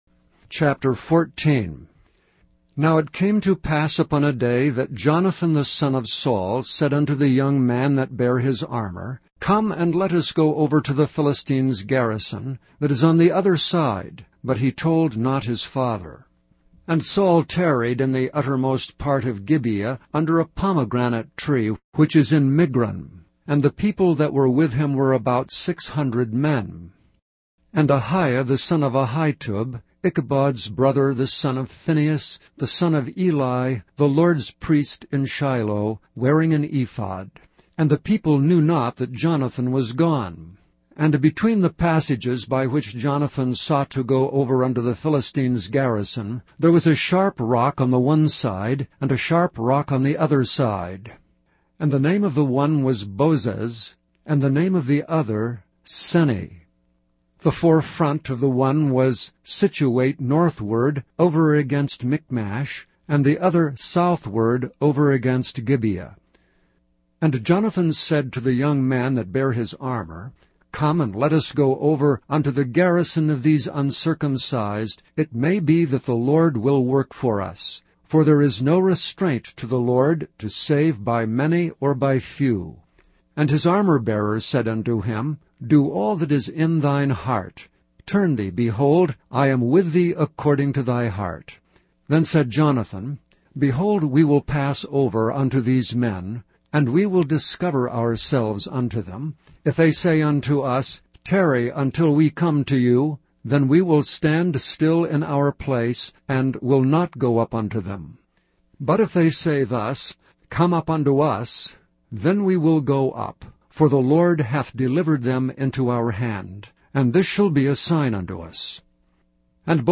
1 Samuel / Bible reading - mp3